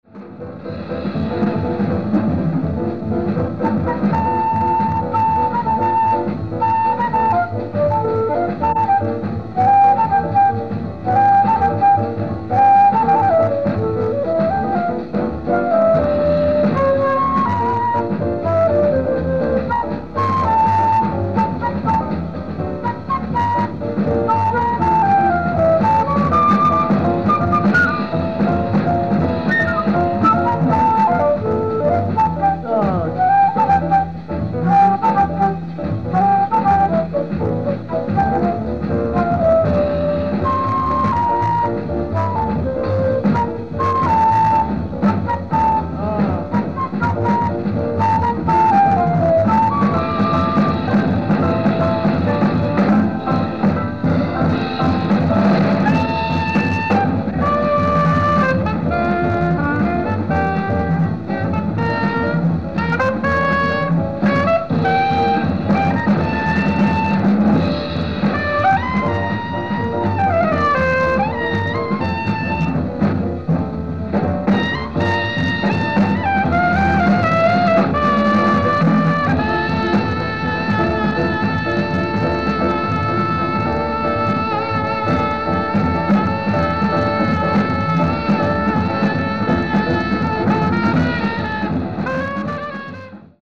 ライブ・アット・クラブ・モンマルトル、コペンハーゲン 10/10/1963